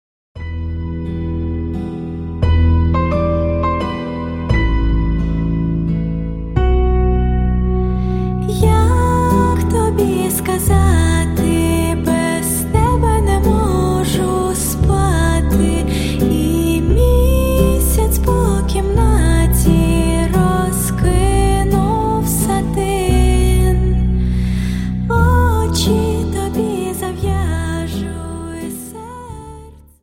Dance: Slow Waltz